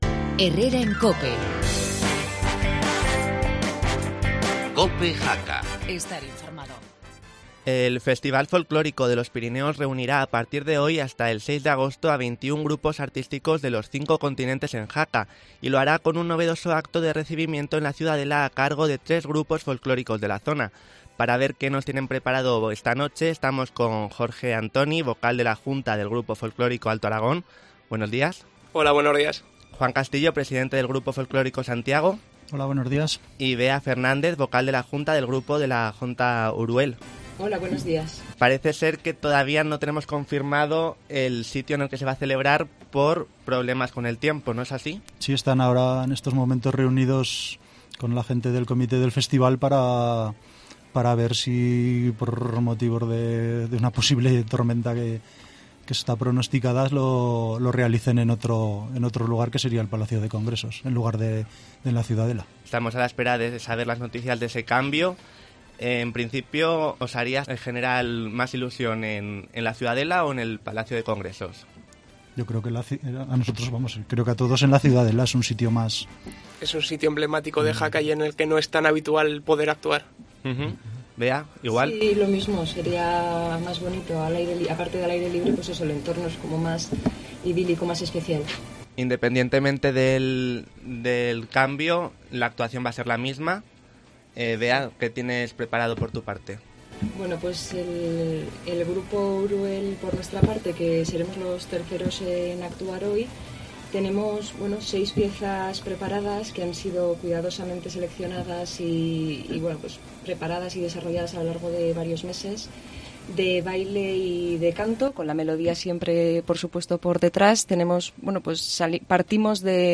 Entrevista en Cope con los grupos locales